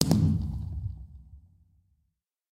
largeBlast_far.mp3